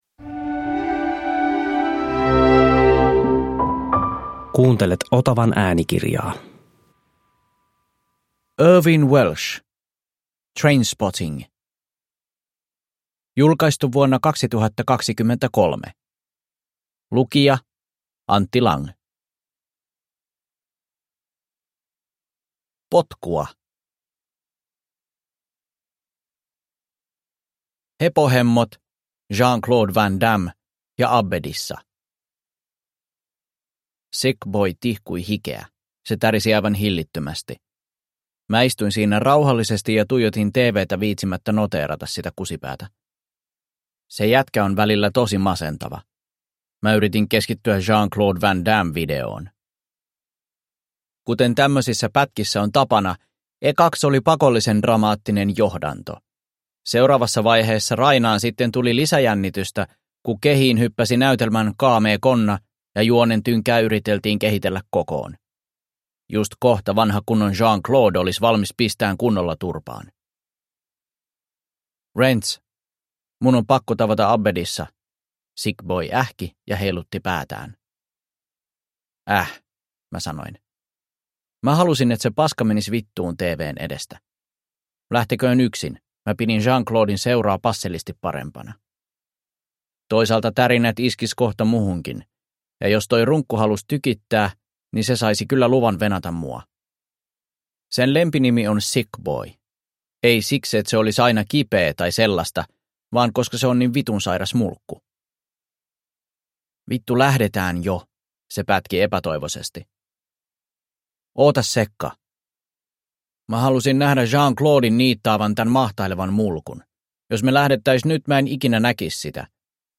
Trainspotting – Ljudbok – Laddas ner